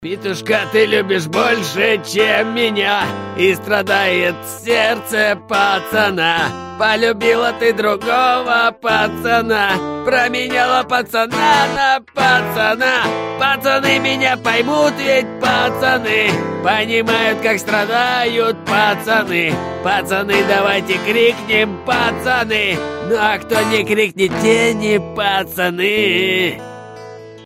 • Качество: 320, Stereo
гитара
грустные
акустическая гитара